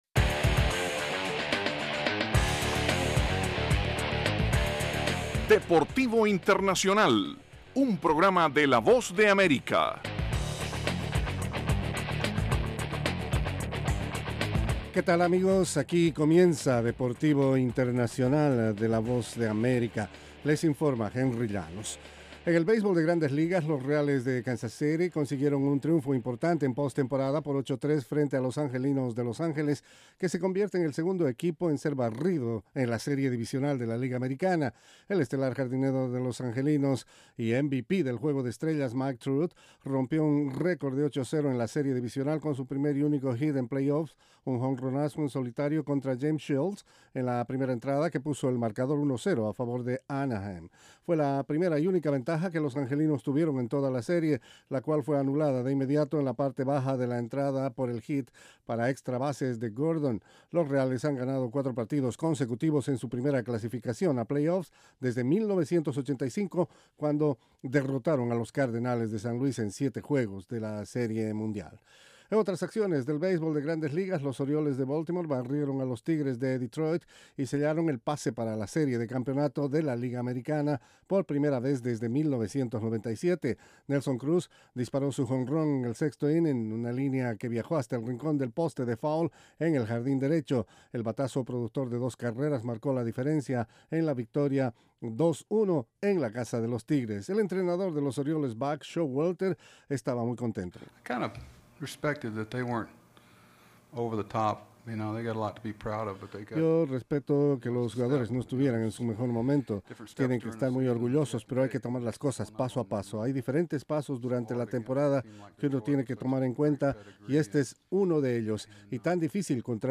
presenta las noticias más relevantes del mundo deportivo desde los estudios de la Voz de América. Deportivo Internacional se emite de lunes a viernes, de 12:05 a 12:10 de la tarde (hora de Washington).